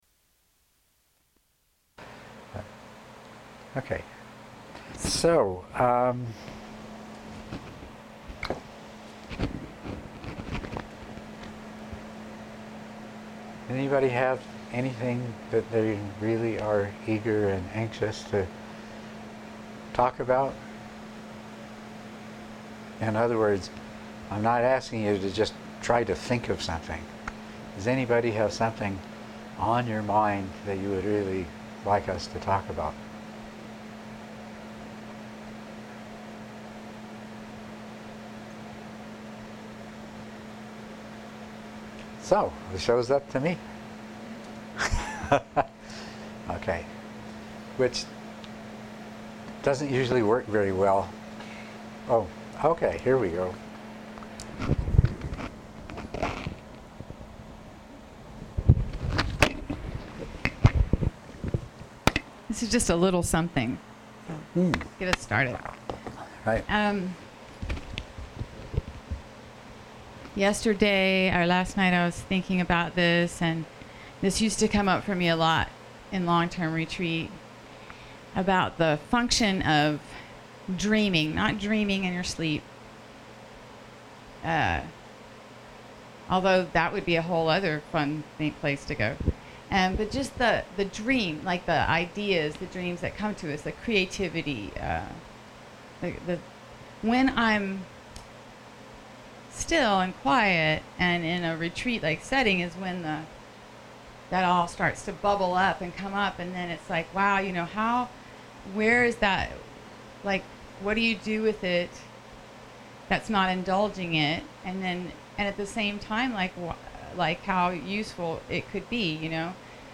MP3 of the Dharma talk at the Stronghold